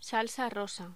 Locución: Salsa rosa
voz
Sonidos: Voz humana